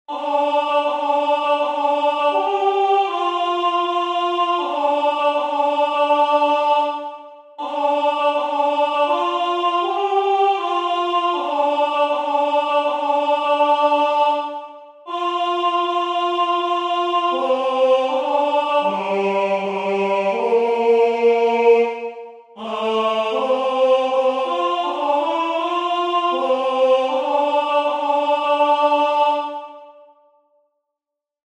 Skaņdarbs lieliski piemērots senās mūzikas praktizēšanai, visas balsis dzied vienā ritmā.
O.Salutaris-Contra.mp3